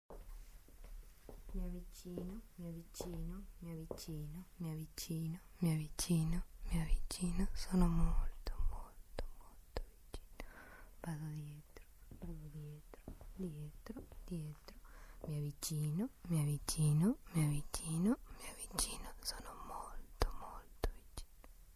3D spatial surround sound "A whisper"
3D Spatial Sounds